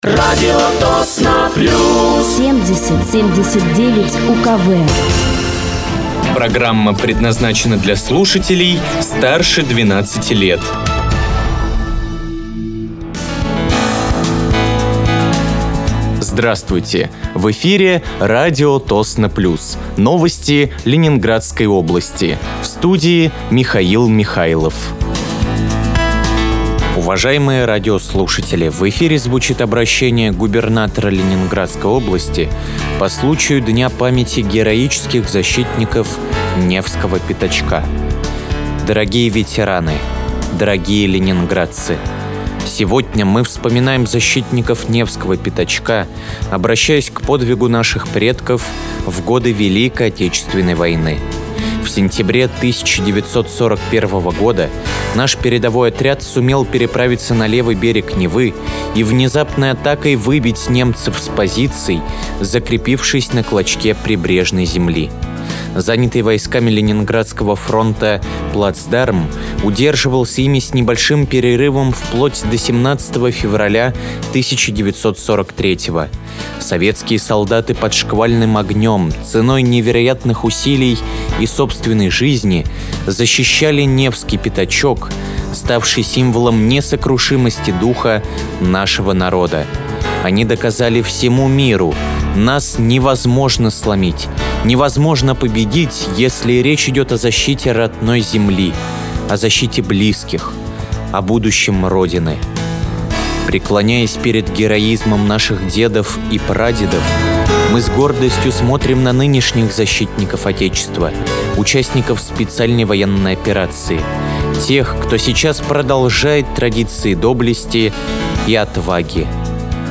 Вы слушаете новости Ленинградской области от 17.02.2025 на радиоканале «Радио Тосно плюс».